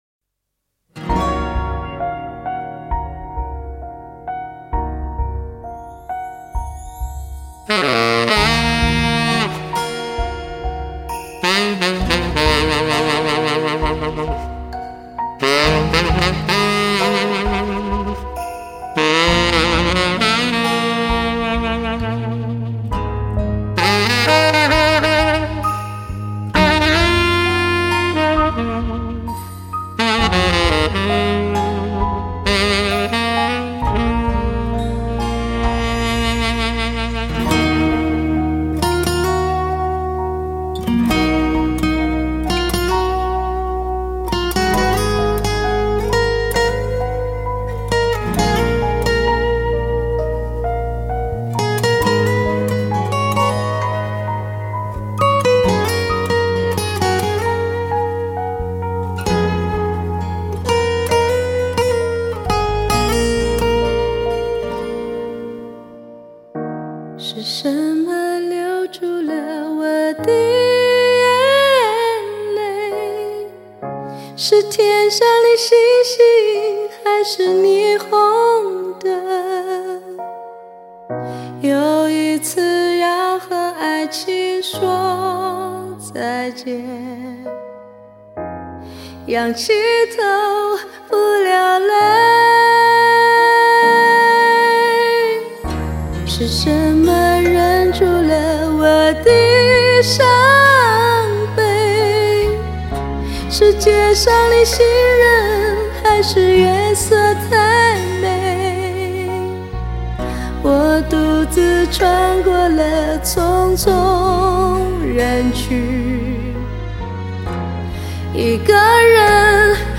她的高音象是绽放的烟火，一冲入云后，又能幻化耍弄出各种花腔。